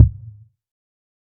Kicks
TC3Kick20.wav